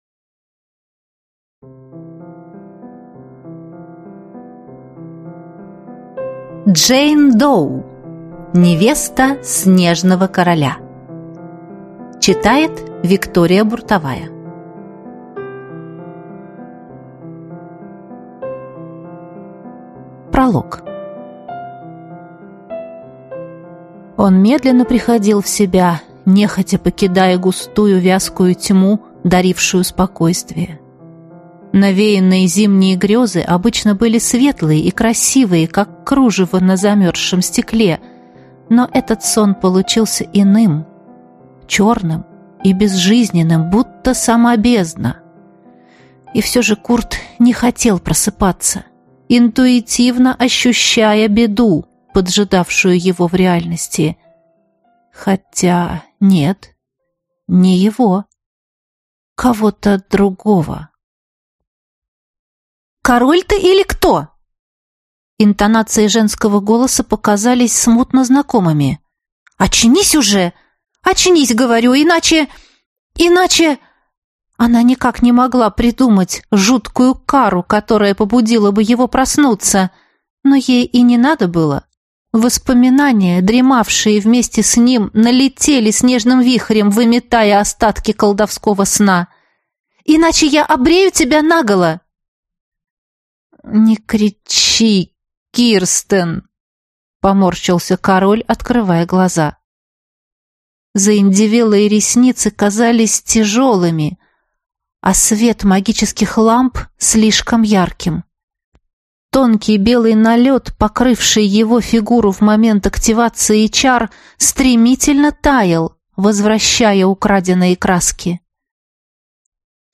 Аудиокнига Невеста снежного короля | Библиотека аудиокниг